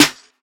• Trap Snare Single Hit D Key 166.wav
Royality free acoustic snare tuned to the D note.
trap-snare-single-hit-d-key-166-TgT.wav